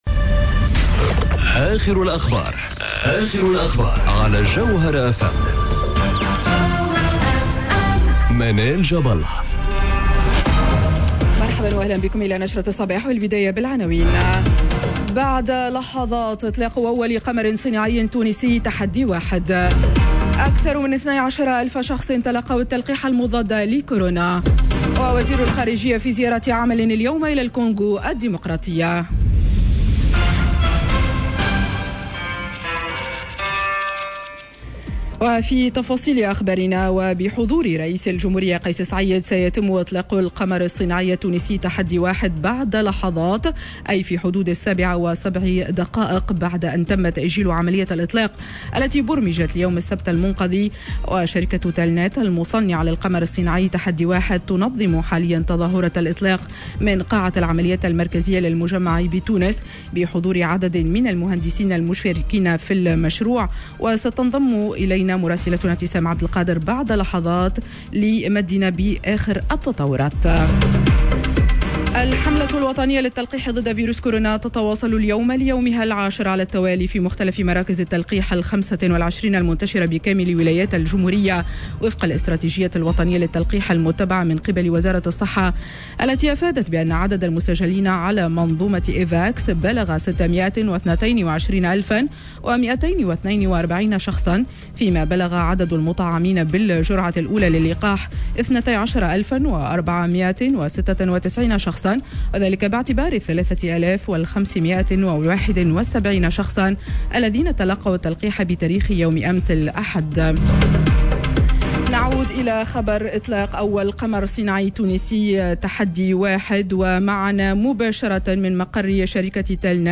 نشرة أخبار السابعة صباحا ليوم الإثنين 22 مارس 2021